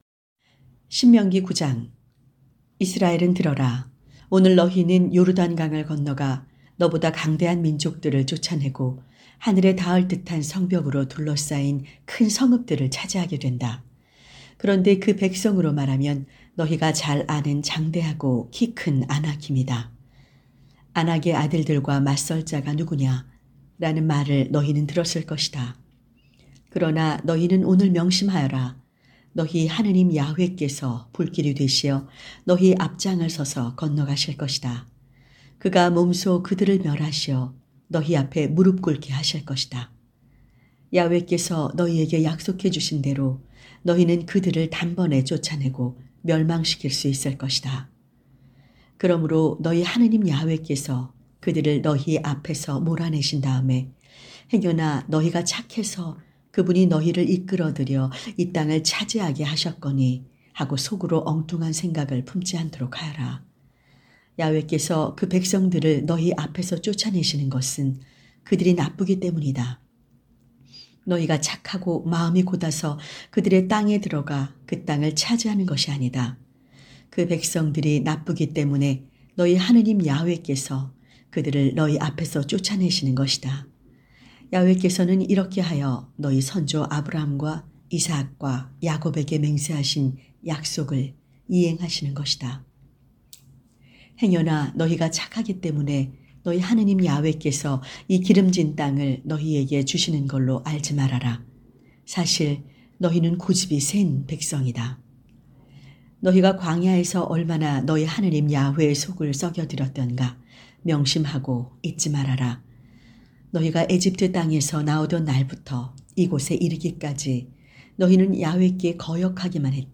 성경 오디오